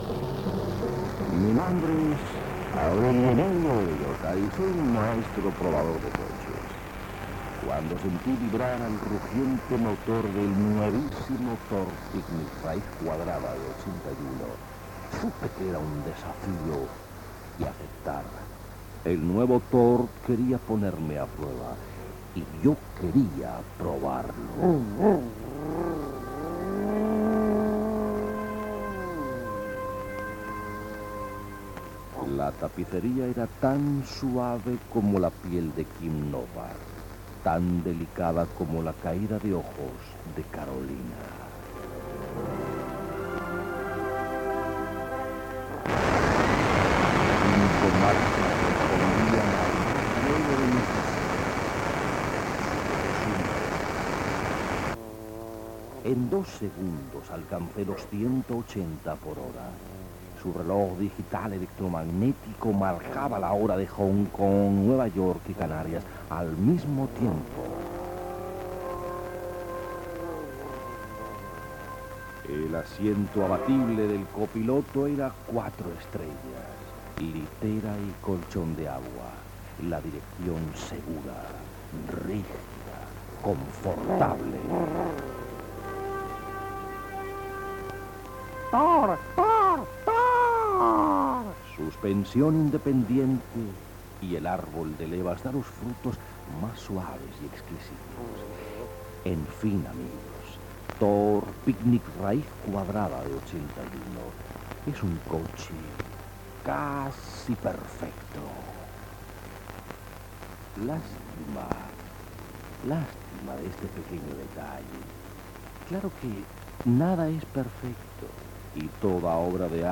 Elogis del cotxe Tor que només té un defecte, paròdia de Ràdio Pica, notícies internacionals de "El altillo del cotolengo", espai musical Gènere radiofònic Entreteniment
Banda FM